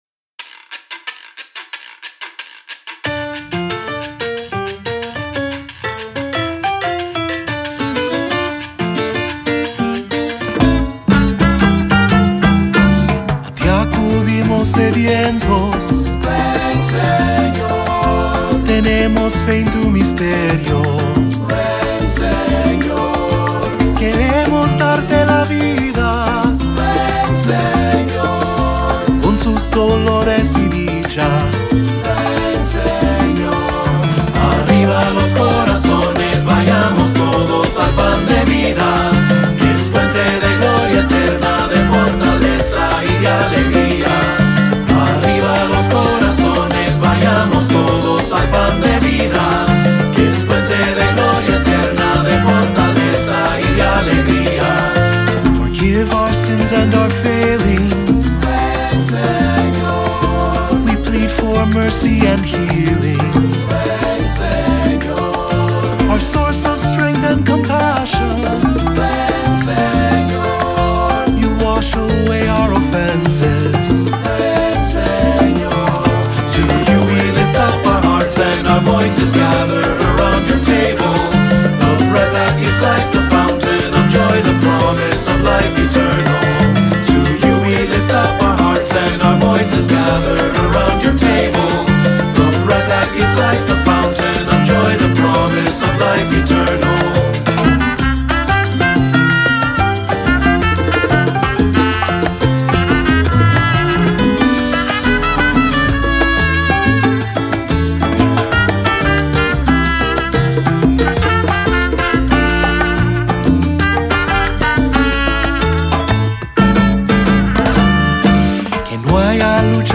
Voicing: Three-part; Cantor; Assembly